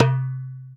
Hand Dumbek.wav